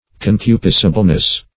Search Result for " concupiscibleness" : The Collaborative International Dictionary of English v.0.48: Concupiscibleness \Con*cu"pis*ci*ble*ness\, n. The state of being concupiscible.